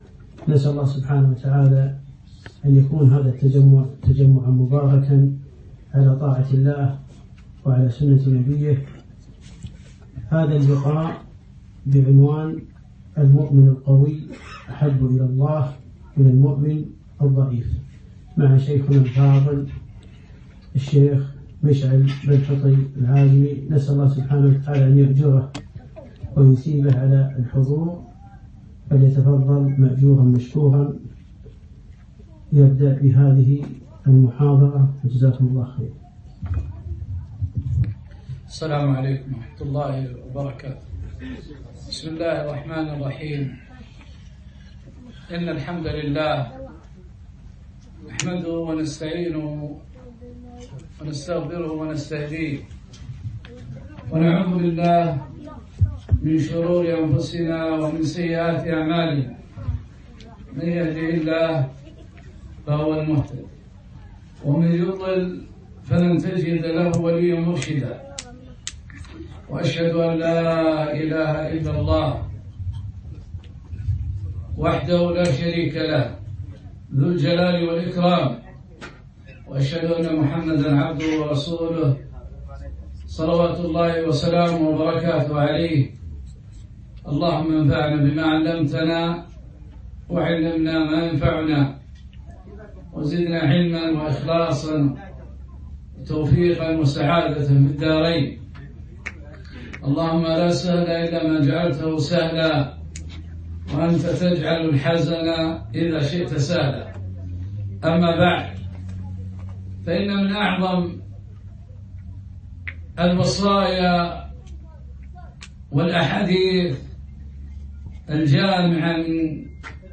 محاضرة - المؤمن القوي أحب الى الله من المؤمن الضعيف